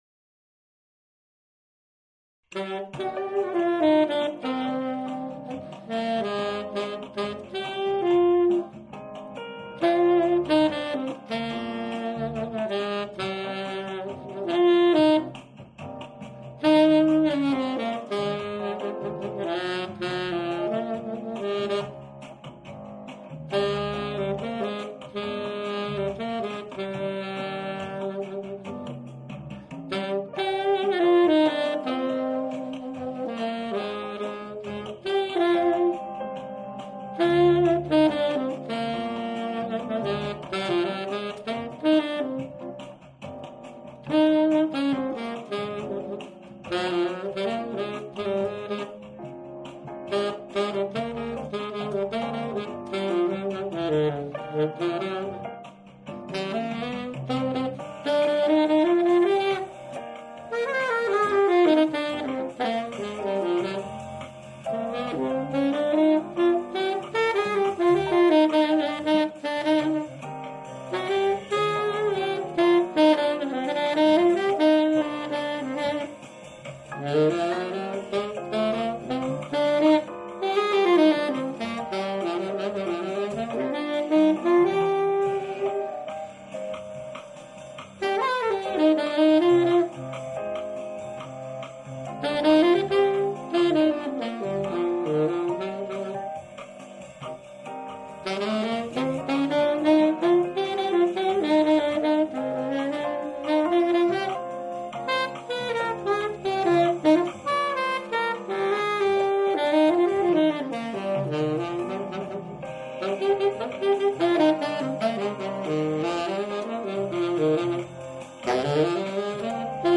TENOR SAX AUDIO RECORDINGS !
All the tenor tunes recorded in Toulouse (France)